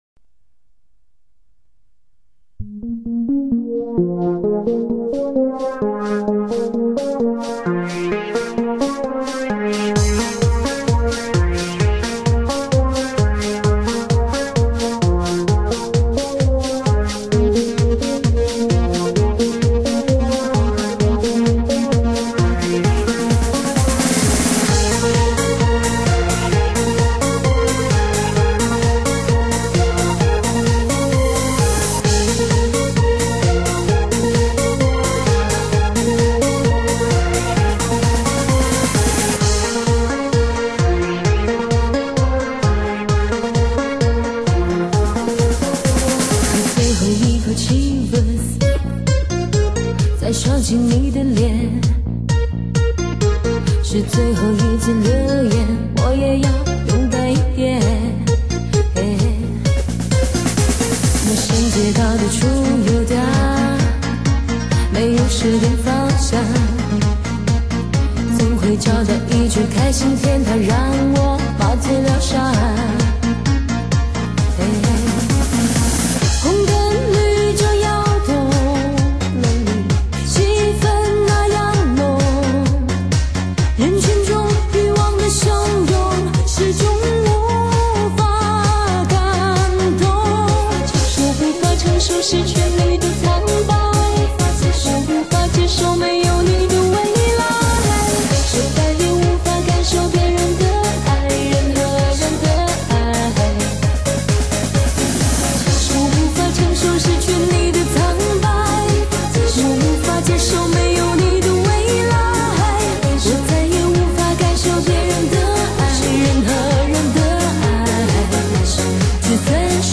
夜场DJ驾车人士必备大碟
劲爆全城 超级音响效果让你一路狂HI,真舞曲 真
DJ,坚持录音棚顶尖音色混音....